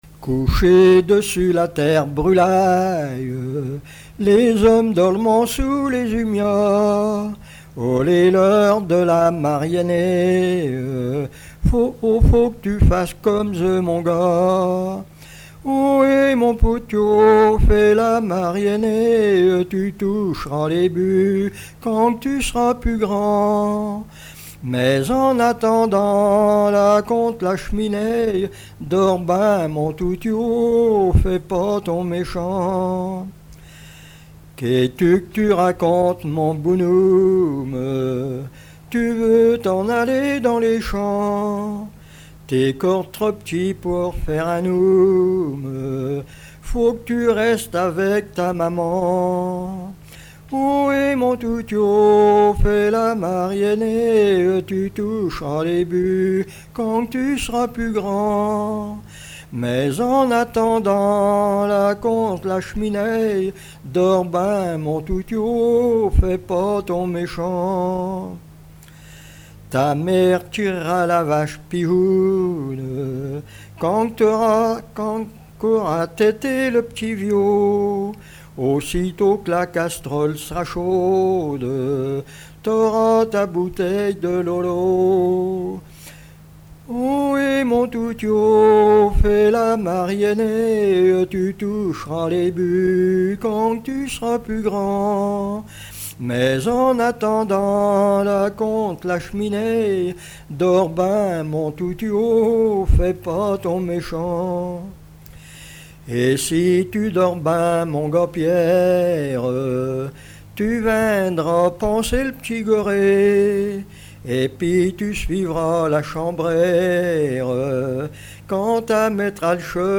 Patois local
Pièce musicale inédite